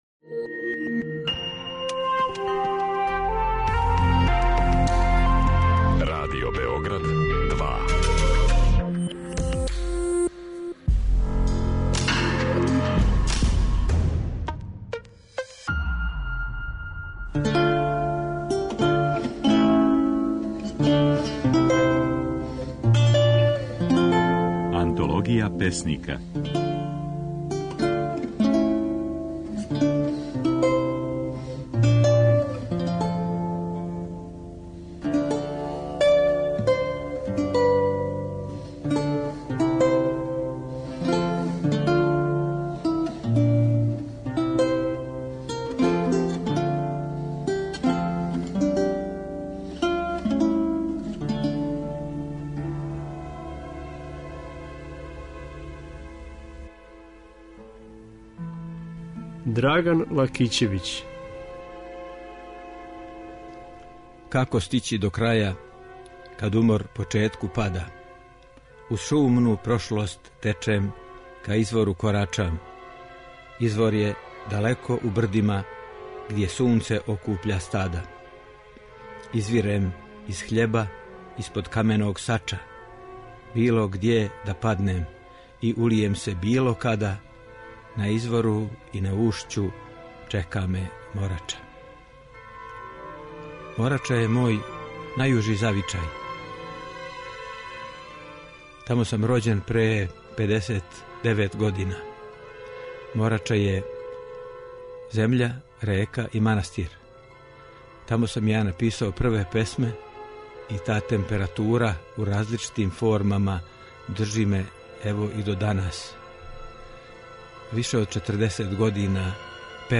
Своје стихове говори